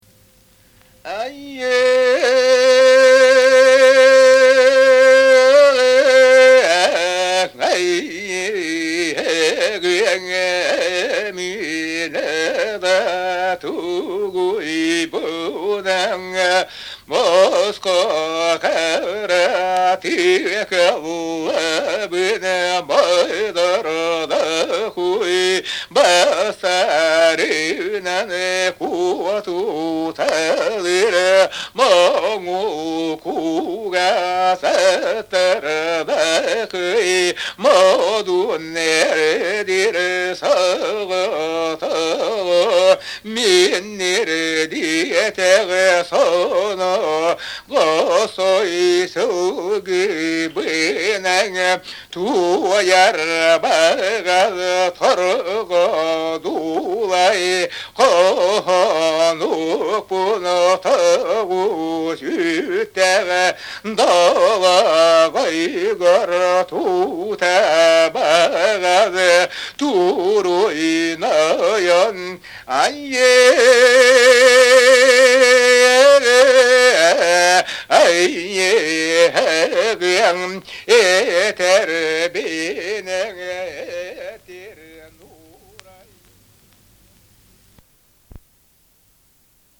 Якутский героический эпос "Кыыс Дэбилийэ"
Песня богатырьского коня. Сказитель и исполнитель